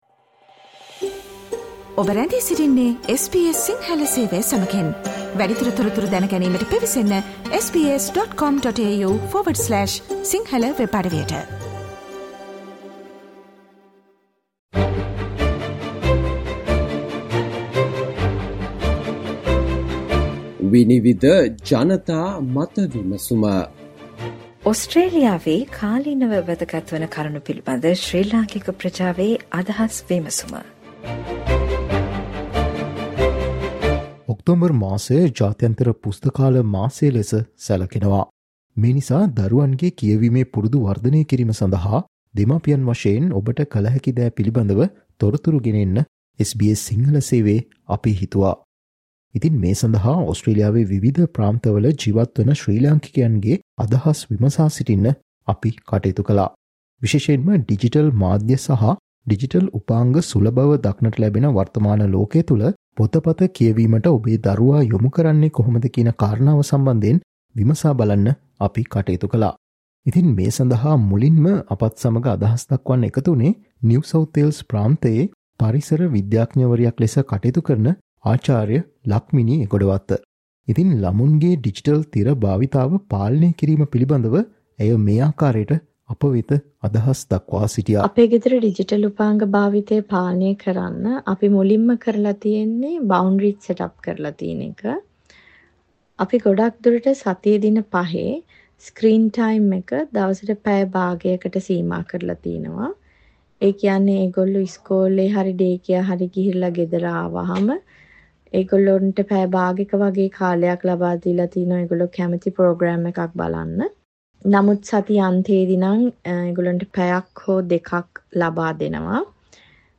Panel discussion consists of the following invitees